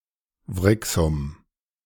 Wrixum (German pronunciation: [ˈvʁɪksʊm]
De-Wrixum.ogg.mp3